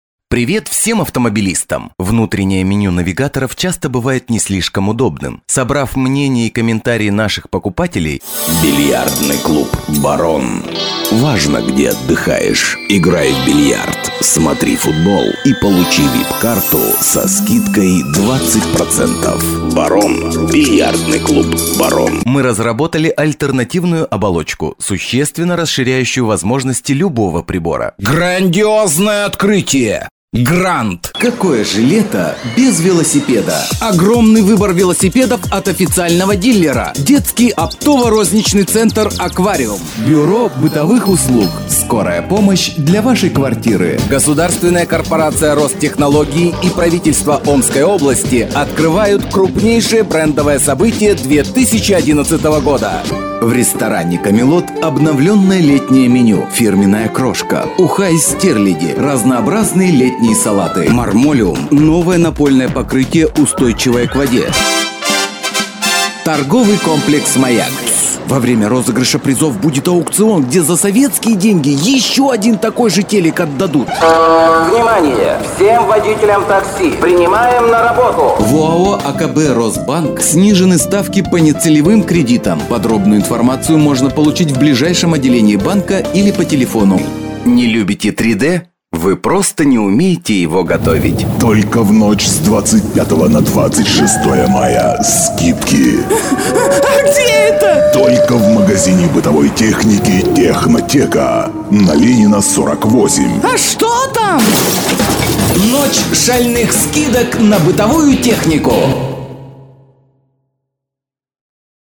Audio-technica AT2020,dbx-286А , Yamaha MG 10/2